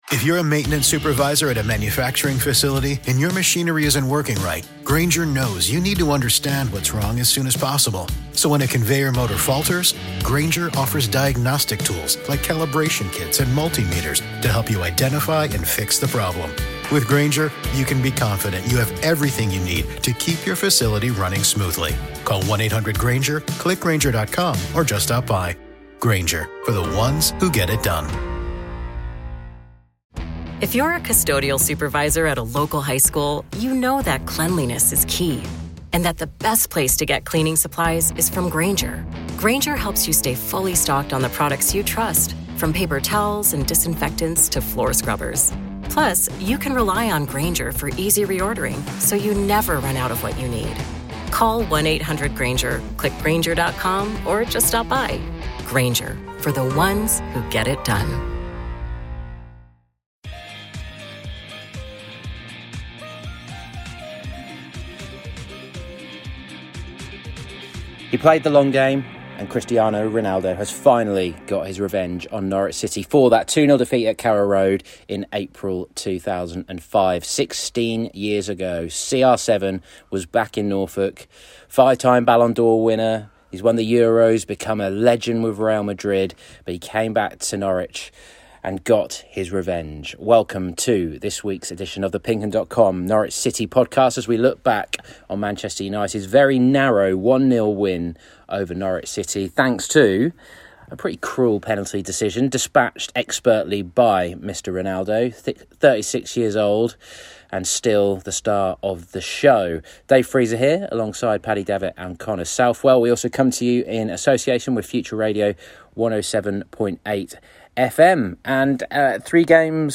Canaries correspondents